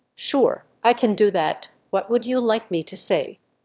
role0_VacuumCleaner_1.wav